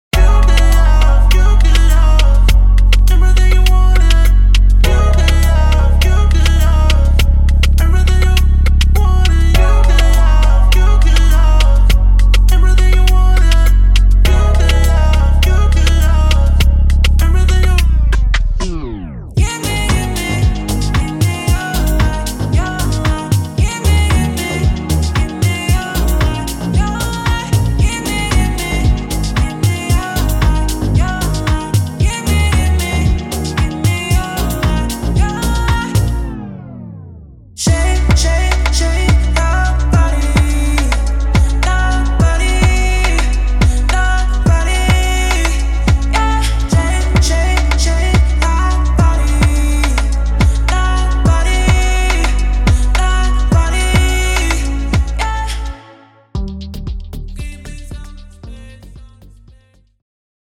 Afrobeats
This is a pack completely full of color and a lot of energy.
• 8 Vocal Hooks Wet
• 20 Drum Loops